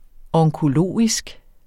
Udtale [ ʌŋkoˈloˀisg ]